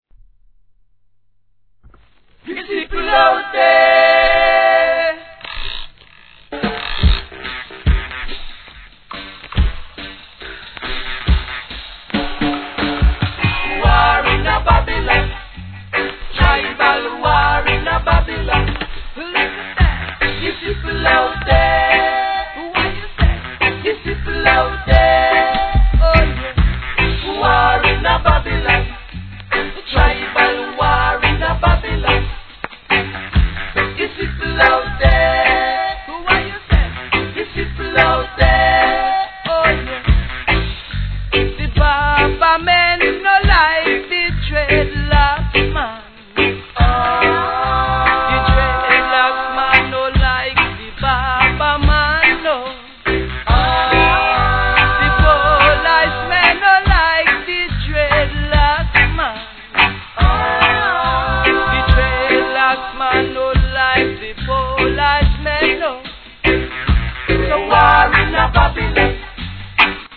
盤質良好ですが頭に若干の歪みのためノイズが序盤に入ります
REGGAE